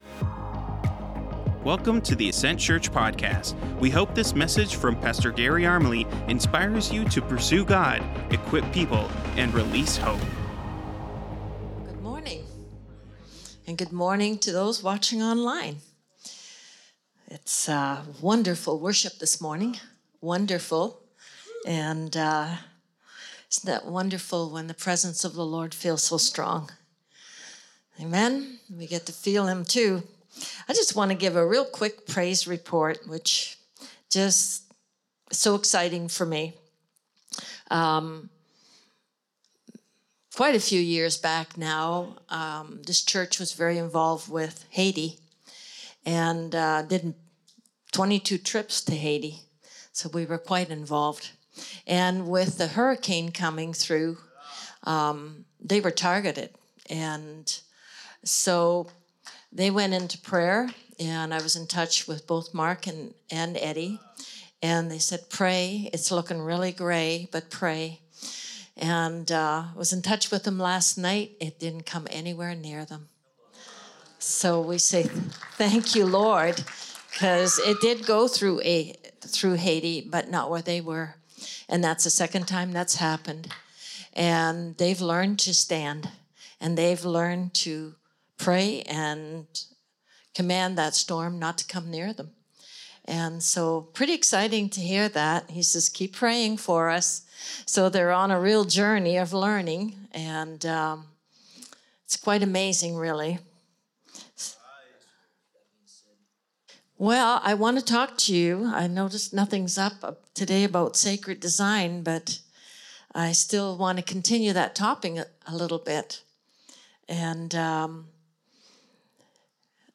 Current Weekly Sermon